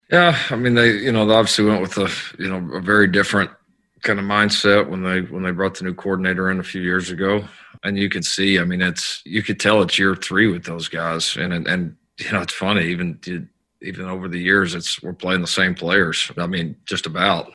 Oklahoma head football coach Lincoln Riley met with the media on Tuesday for his weekly press conference ahead of Bedlam 2020.